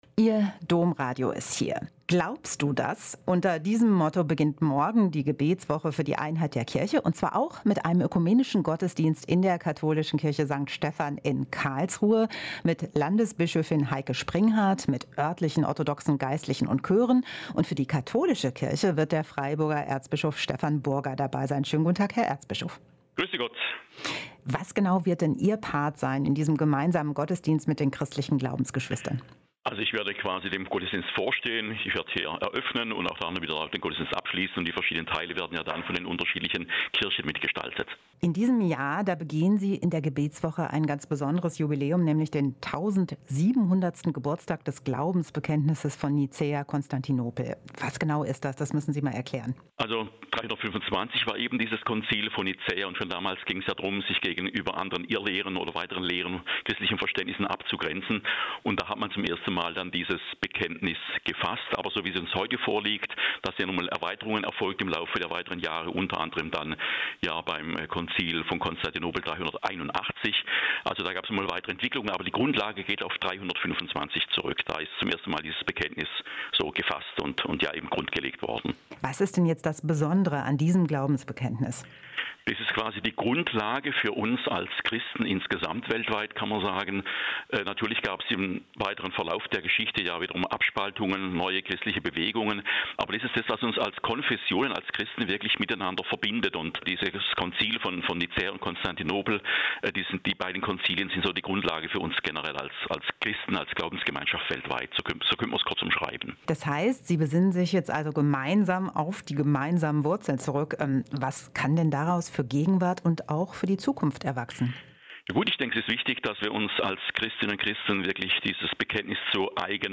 Ein Interview mit Erzbischof Stephan Burger (Erzbischof von Freiburg)